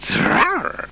00215_Sound_tiger.mp3